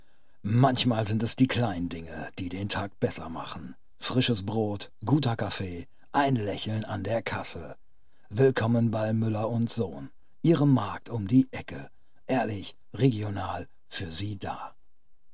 Werbespot
Studio-quality recordings.